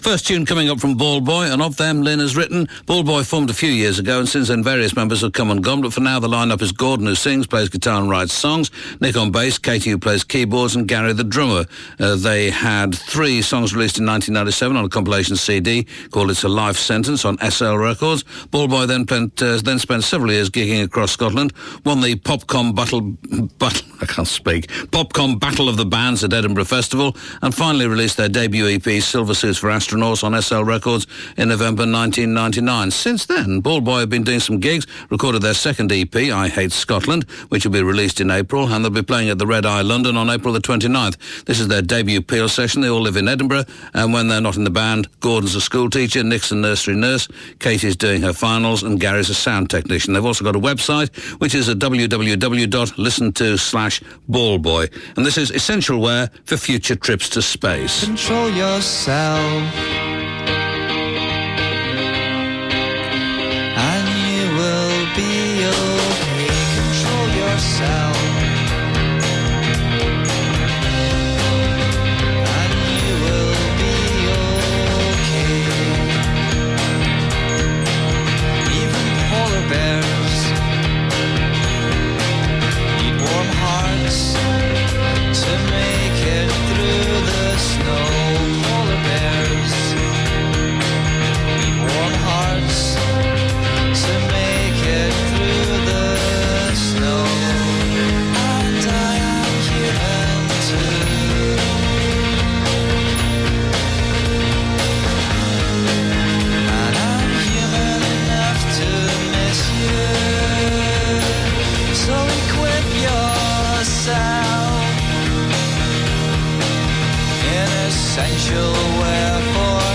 guitar
bass
drums
indie